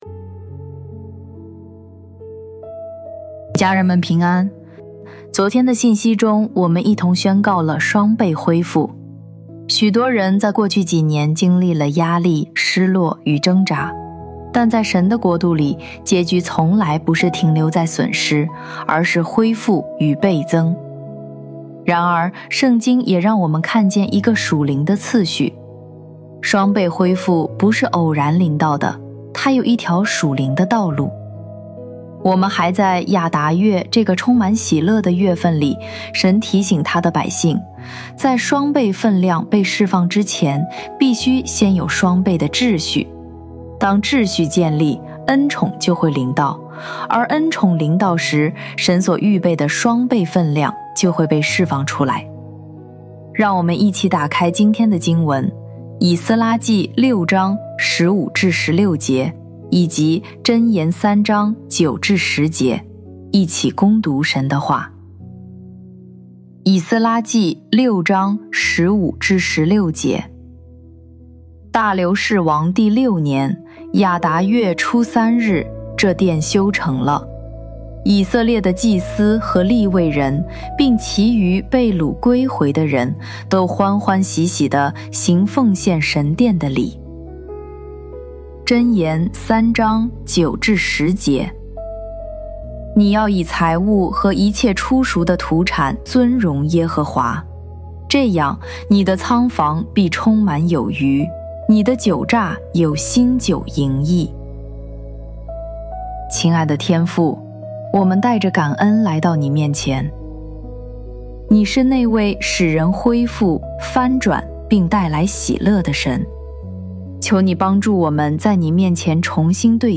本篇由微牧之歌翻译，音频，祷告 从「双倍恢复」走向「双倍的季节」 音频 家人们平安， 昨天的信息中，我们一同…